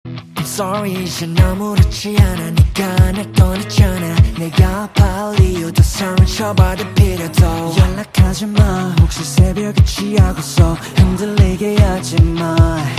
PRDBF_Rhythm_Guitar_Splash__150_BPM_C#minor